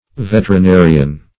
veterinarian \vet`er*i*na"ri*an\
(v[e^]t`[~e]r*[i^]*n[=a]"r[i^]*an), n. [L. veterinarius. See